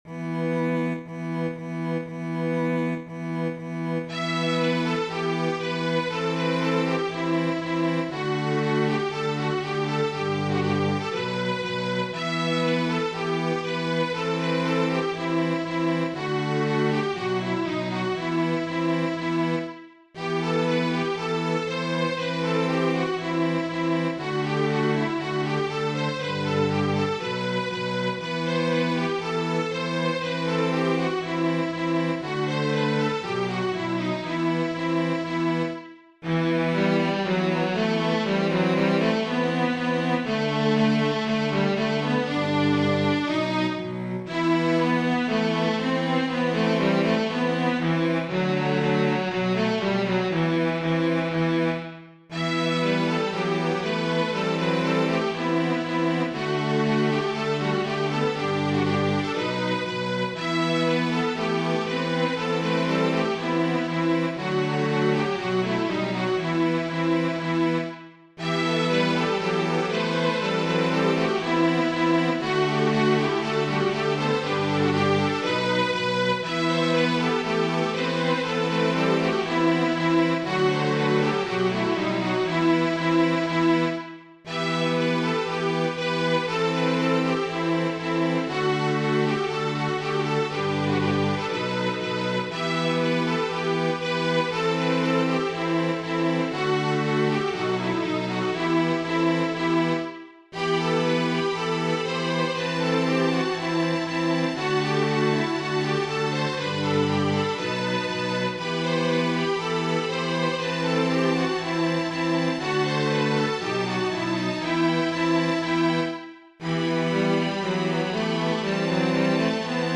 Gjord för violinkvintett/kvartett med flöjt, solo och kör.
Jag har försökt få samma stämning och stil genom konserten i de åtta olika stycken den består av.